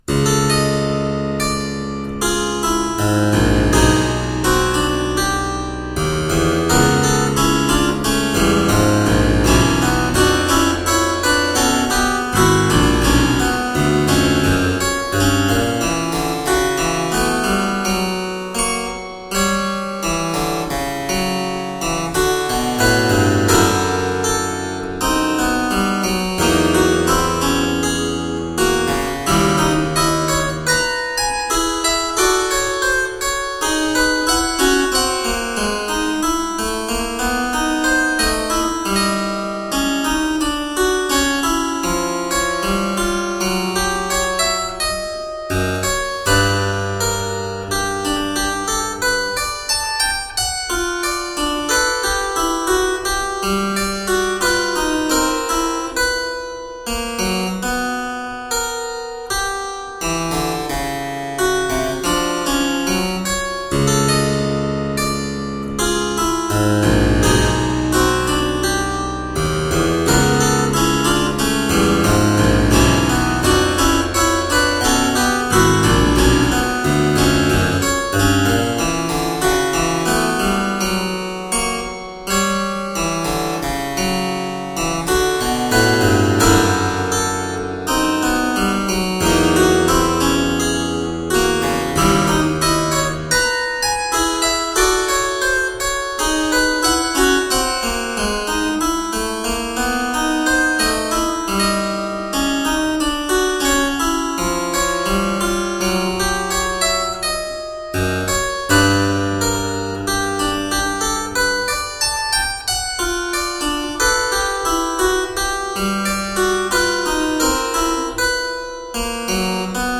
Harpsichord and fortepiano classics.
Rich tones, reflective work.